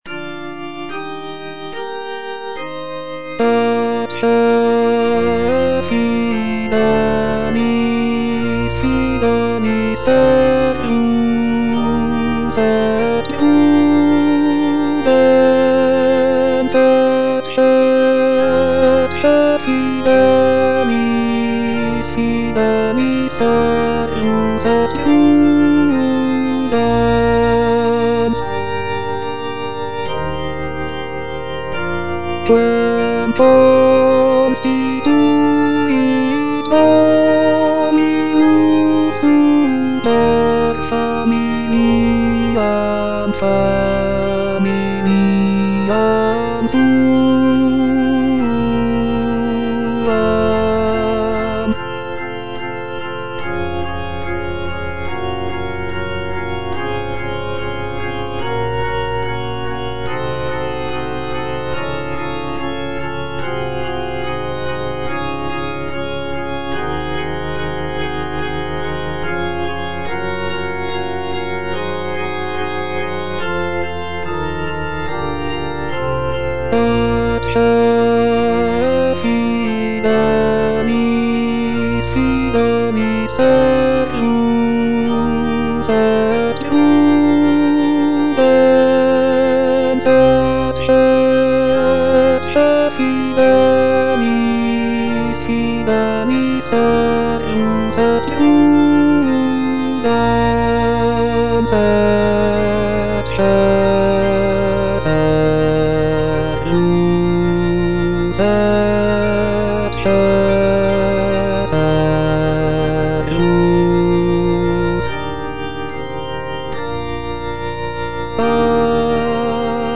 G. FAURÉ - ECCE FIDELIS SERVUS Baritone (Voice with metronome) Ads stop:
The piece is written for four-part mixed choir and organ, and is based on a biblical text from the Book of Matthew.